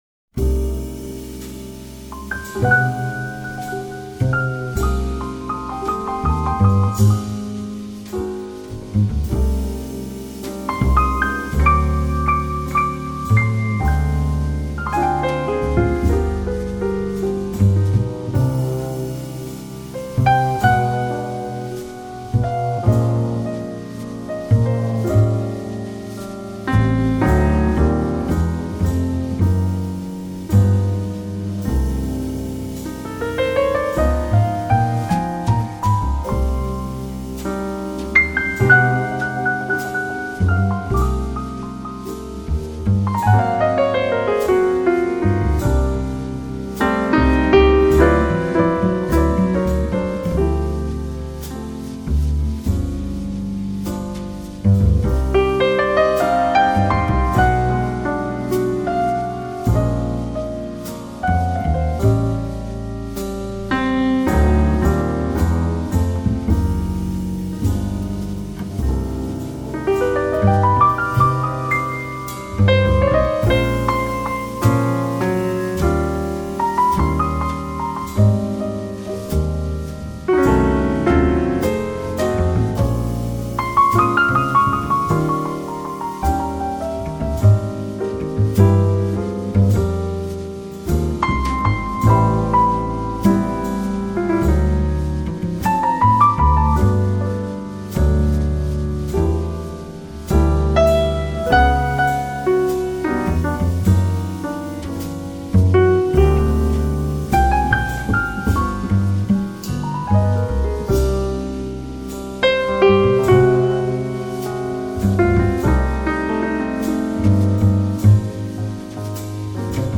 的睿智与优雅，但是他的钢琴很流畅，很细腻， 是温柔而沉静的，你不需要
爵士鋼琴專輯